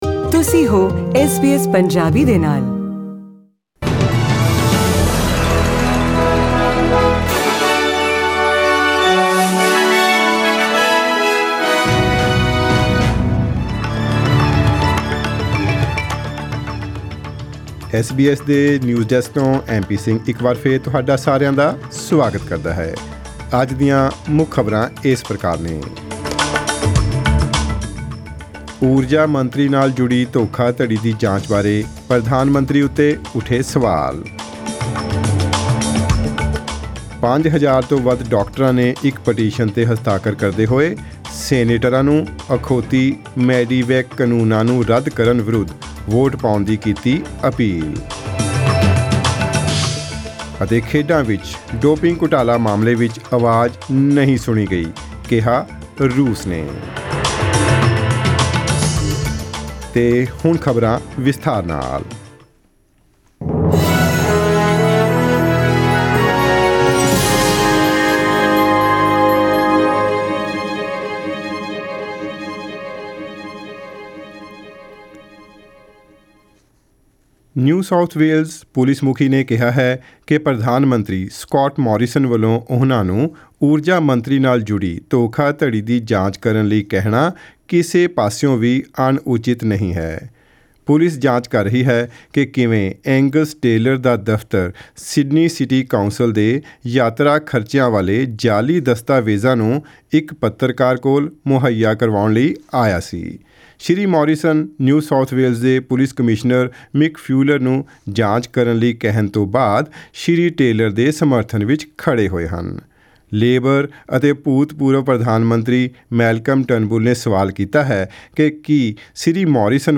Here are the headlines in tonight’s news bulletin: